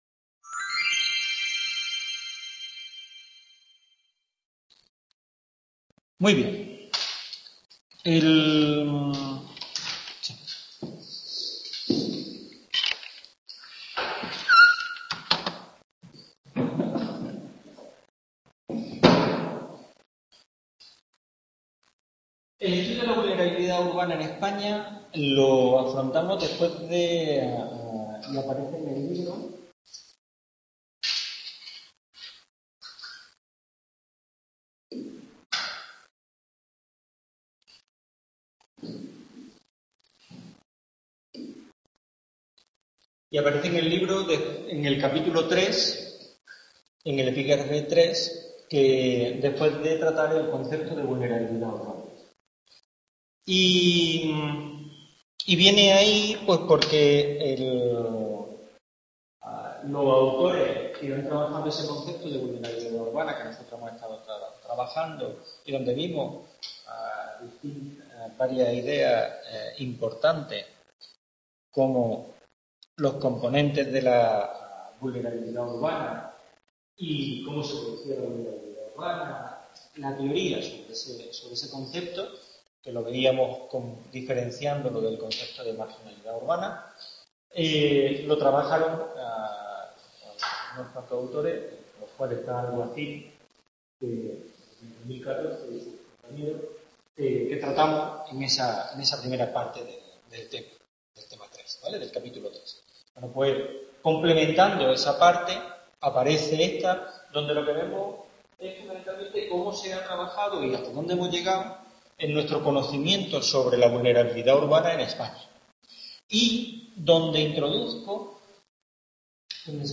Tutoria sobre el Tema del Estudio de la Vulnerabilidad Social en España